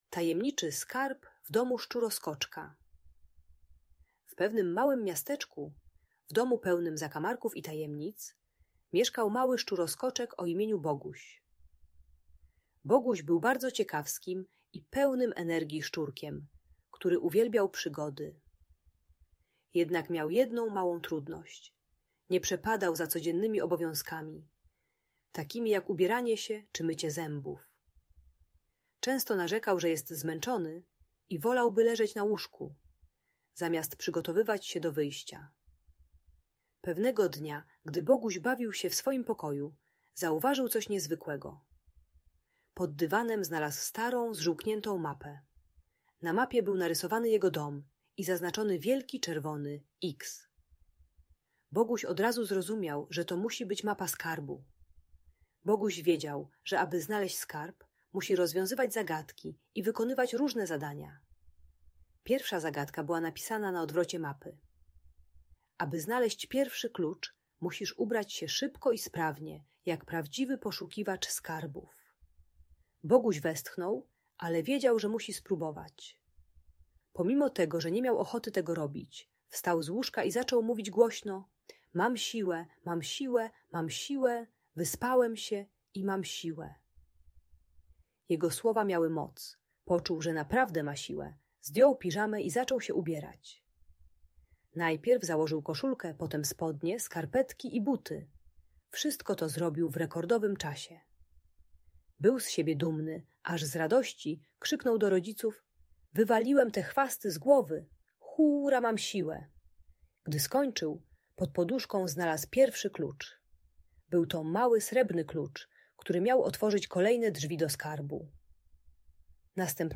Historia o Tajemniczym Skarbie w Domu Szczuroskoczka - Audiobajka